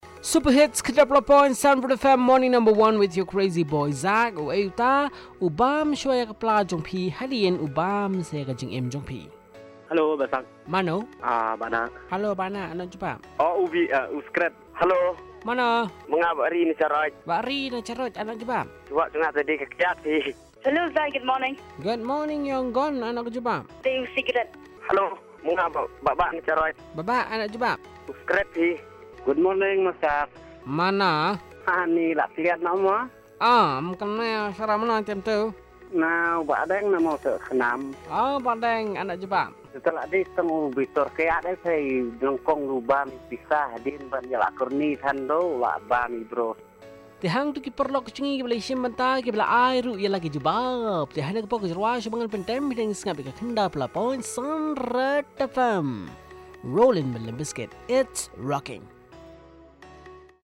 Callers